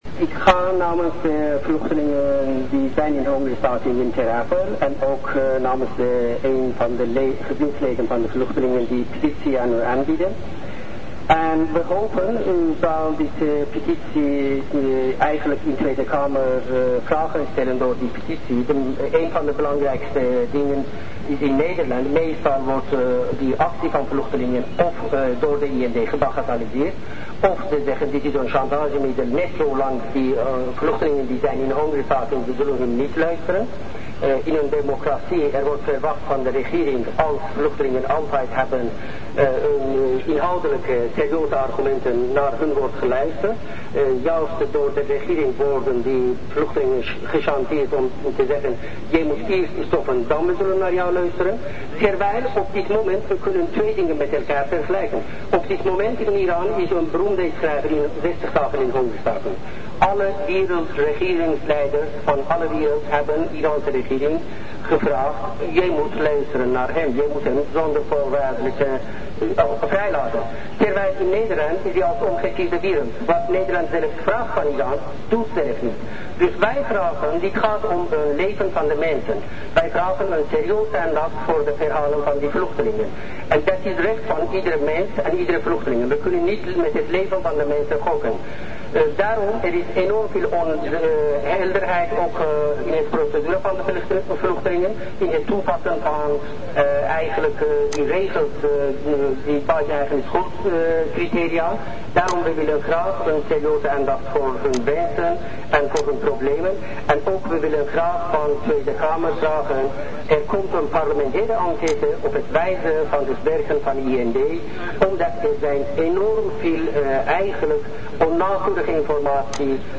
Afgelopen woensdag was er in Den Haag een protestmanifestatie tegen de behandeling van vluchtelingen in de vertrekcentra Ter Apel en Vught. De manifestatie was tevens ter ondersteuning van de Iraanse hongerstakers.